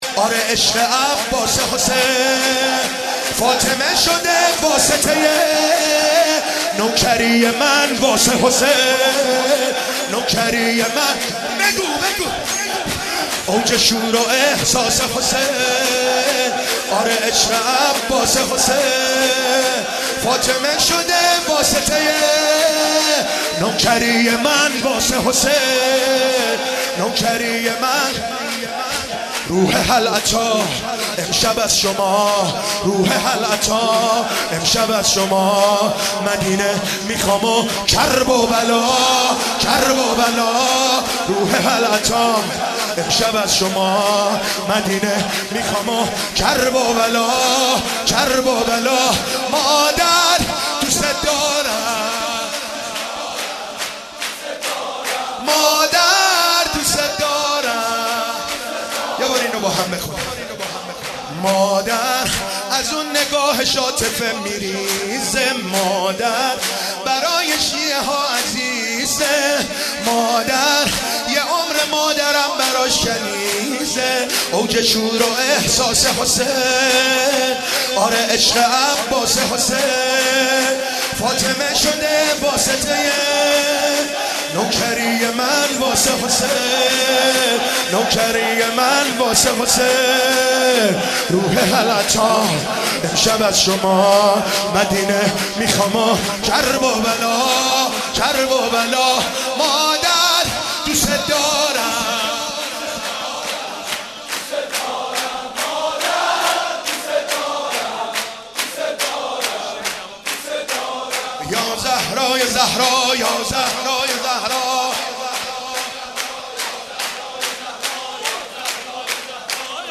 مدیحه سرایی ، سرود